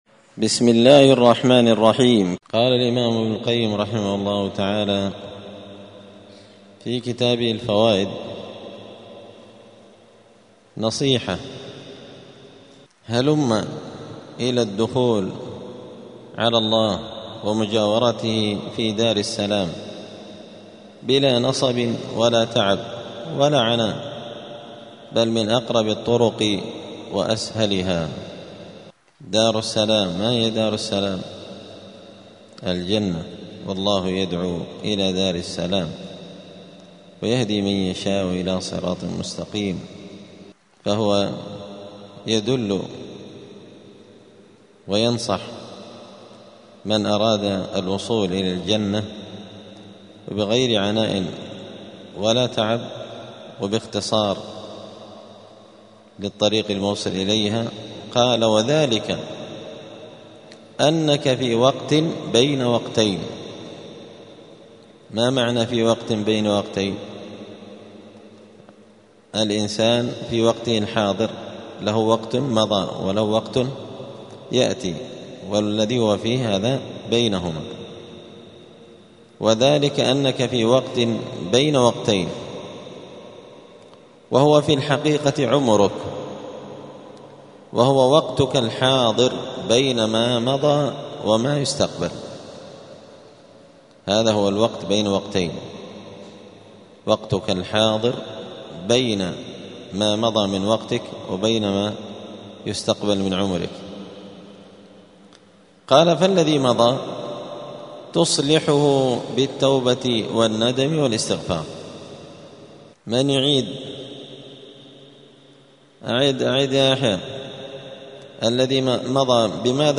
*الدرس الرابع والستون (64) {فصل: هلم إلى الدخول على الله ومجاورته إلى دار السلام}*